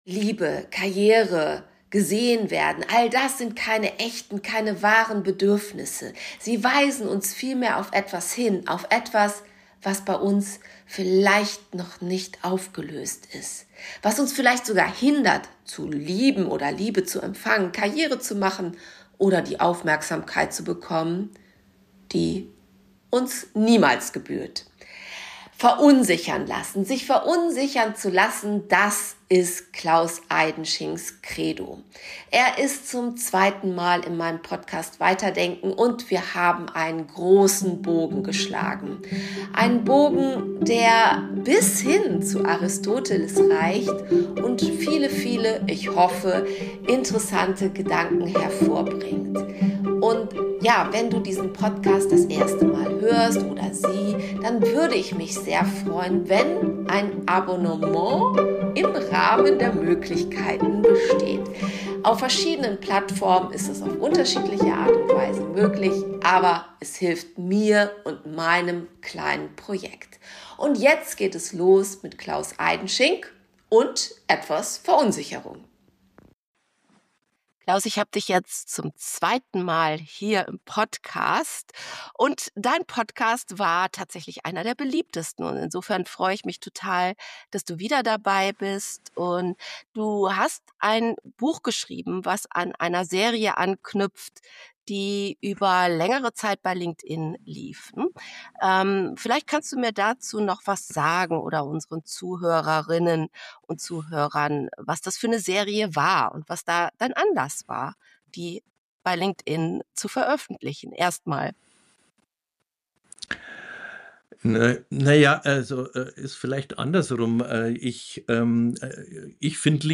Ein zentrales Thema im Interview sind Bedürfnisse. Weiterhin geht es um die Bedeutung innerer Konflikte Thematisiert wird auch das "innere Kind" und damit verbundene Fehlannahmen.